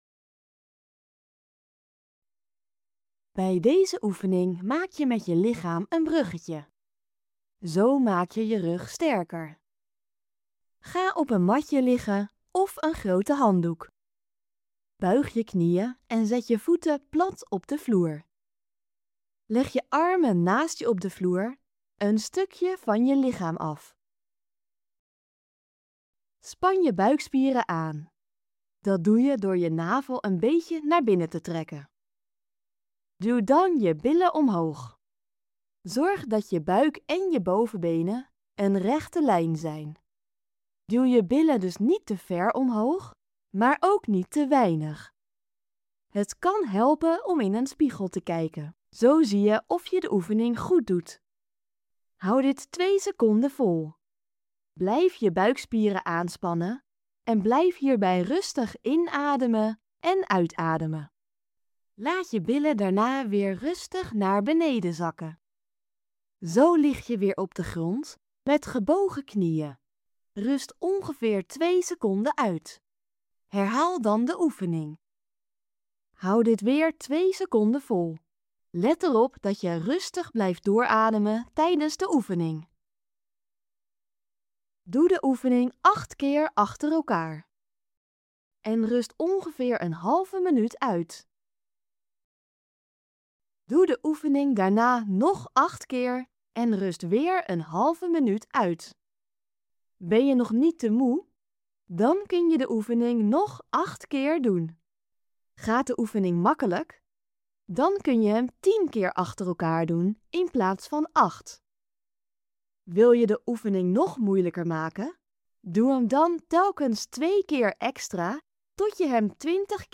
Geluidsbestand voor als je slecht ziet of blind bent